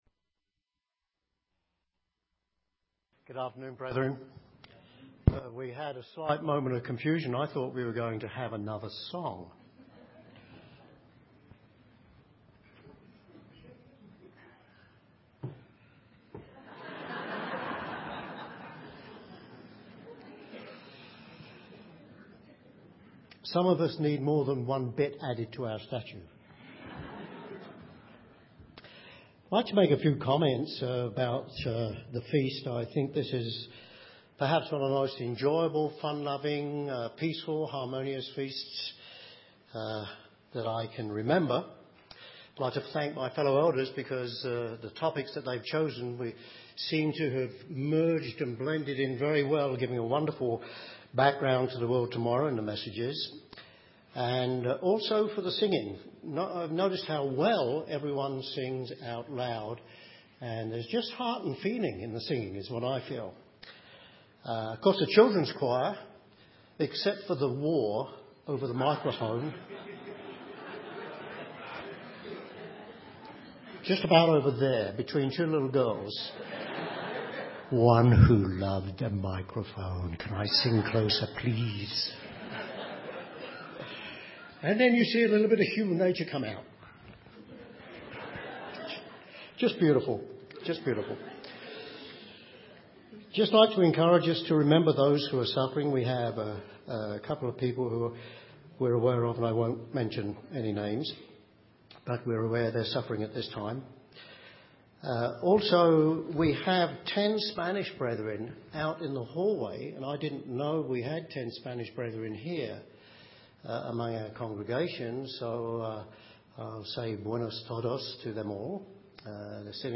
This sermon was given at the Steamboat Springs, Colorado 2012 Feast site.